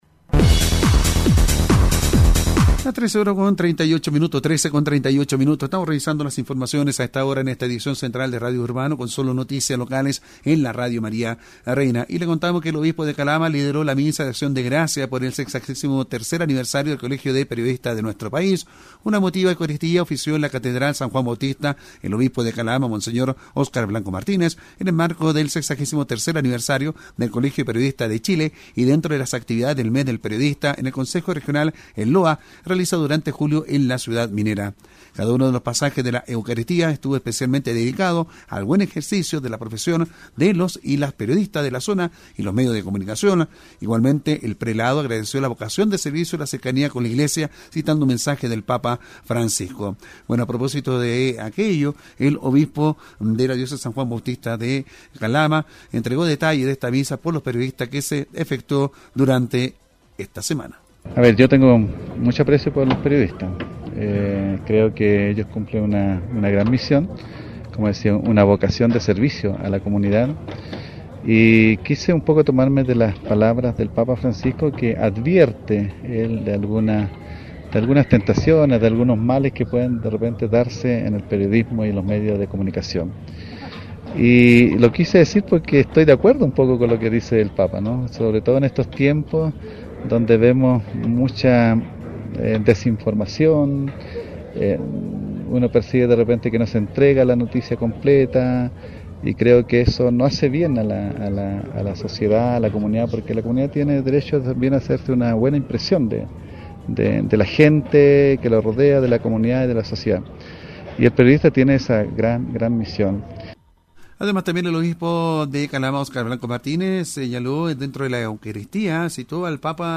Así informó sobre la actividad el noticiero Radio Urbano de Radio María Reina, dependiente de la Diócesis San Juan Bautista.